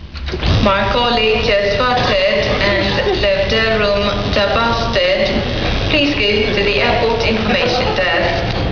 Announcers were tricked into saying them under the pretence that they were foreign names.
"We'd go and sit on the balcony at Terminal 3 at Heathrow, directly under one of the speakers as the roof is low.
We put the tape machine in our bag with the microphone poking out of the top.